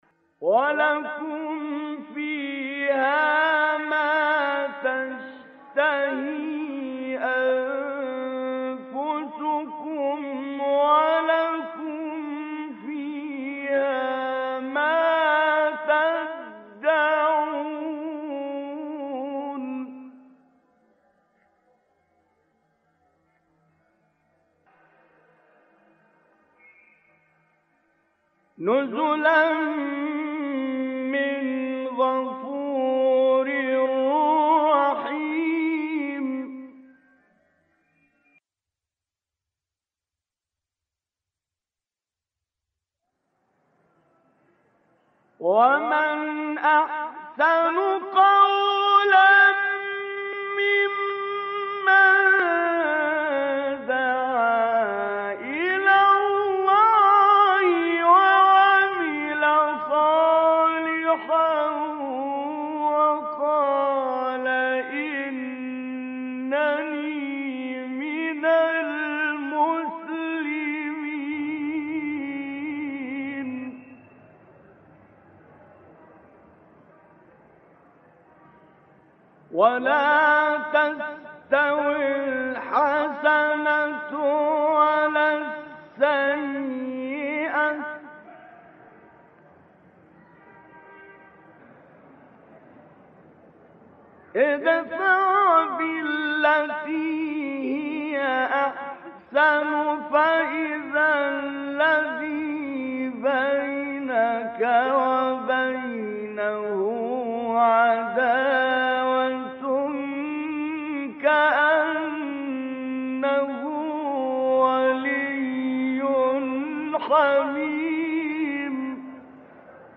بخشی از سوره فصلت با صدای طنطاوی | نغمات قرآن | دانلود تلاوت قرآن